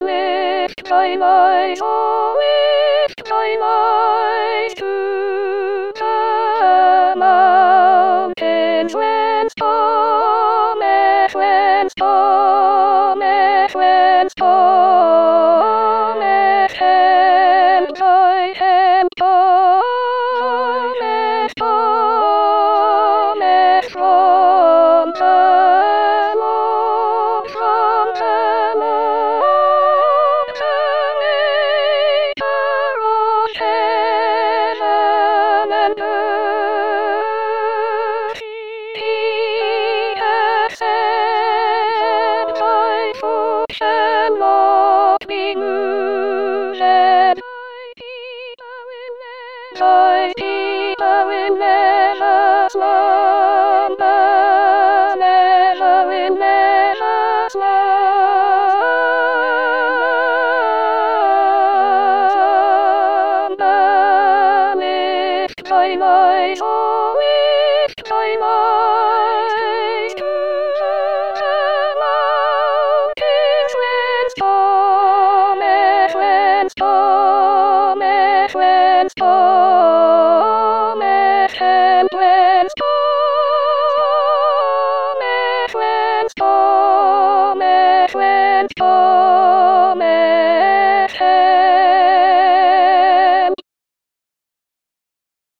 Soprano Soprano 2